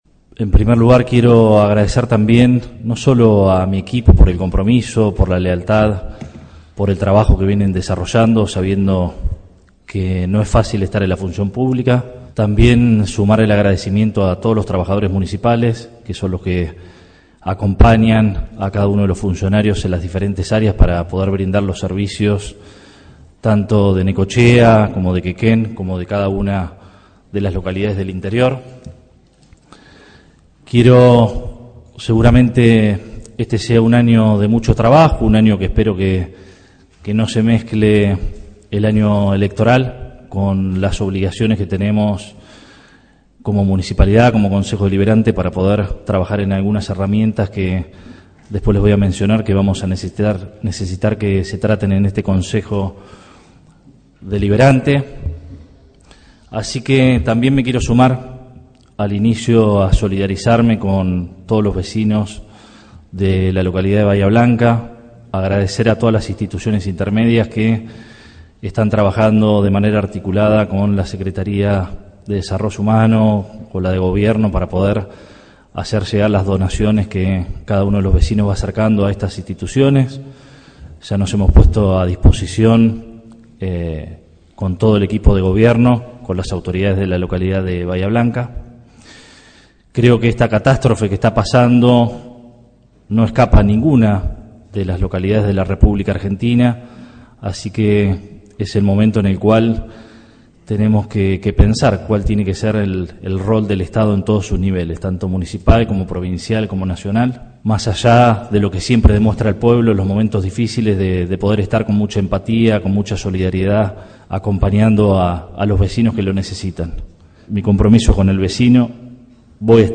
Con el discurso del Intendente Municipal quedó inaugurado el nuevo ciclo de Sesiones Ordinarias en el recinto del Concejo Deliberante.
En la noche del lunes 10 de marzo se realizó la Sesión Inaugural del año 2025 en el Honorable Concejo Deliberante de Necochea, acto que contó con el discurso del Intendente Arturo Rojas, y que fue encabezado por el Presidente del cuerpo deliberativo local, Guillermo Sánchez.